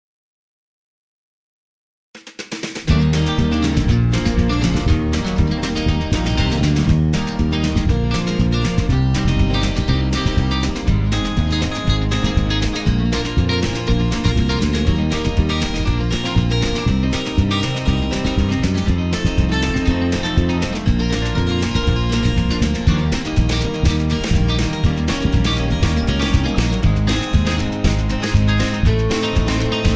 karaoke, backing tracks
rock